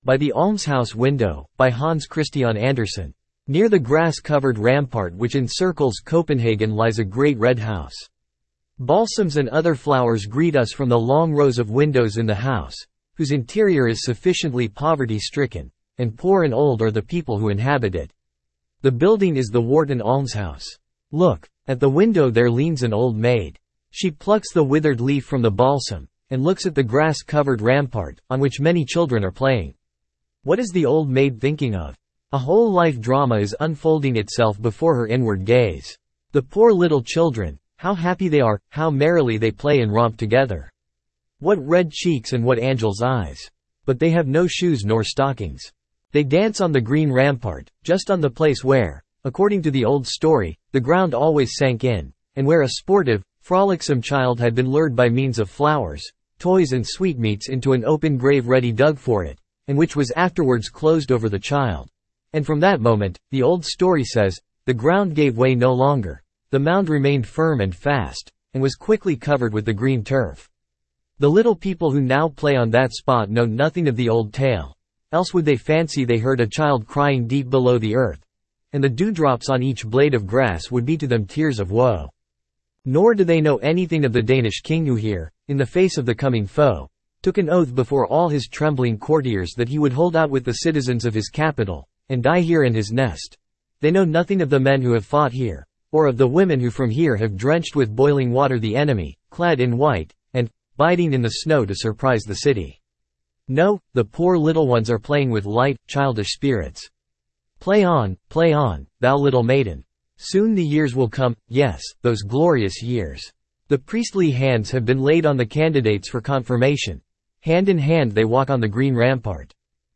Standard (Male)
by-the-almshouse-window-en-US-Standard-D-04c7b9e5.mp3